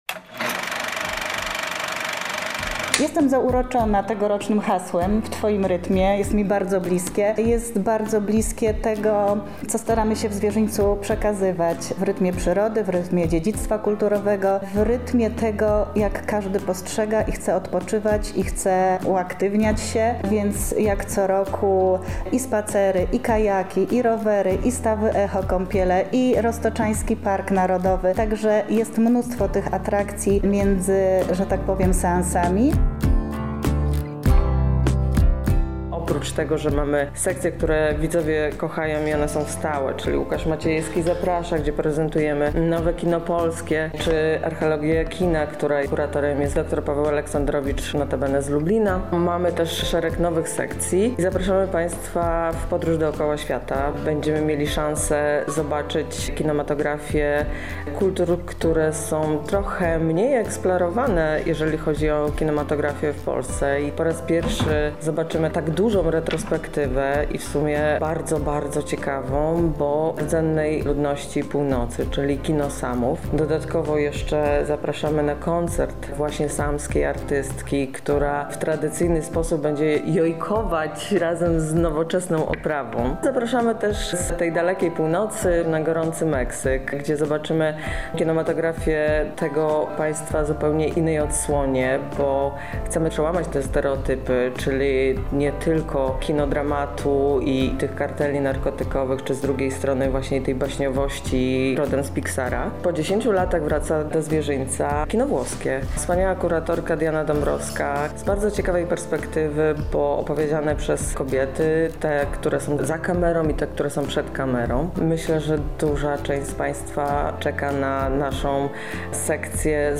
Więcej o wydarzeniu mówią: Edyta Wolanin, burmistrz Zwierzyńca